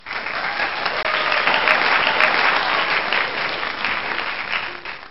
applause7